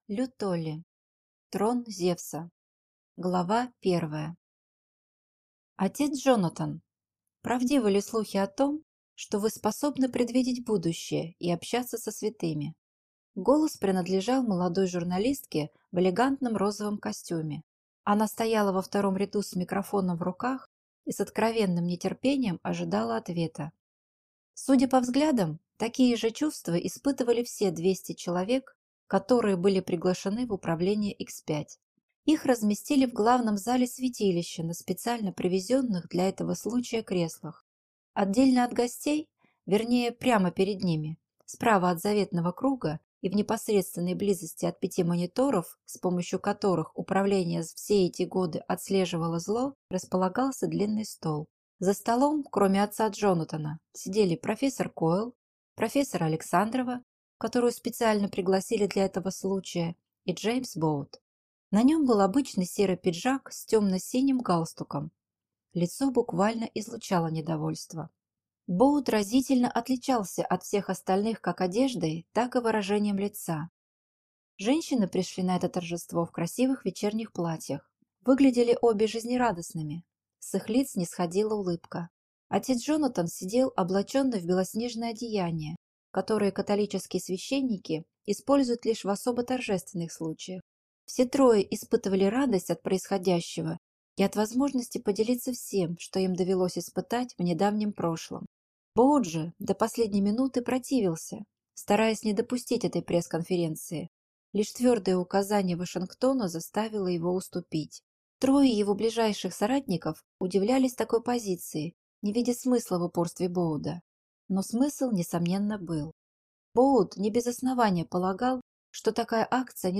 Аудиокнига Трон Зевса | Библиотека аудиокниг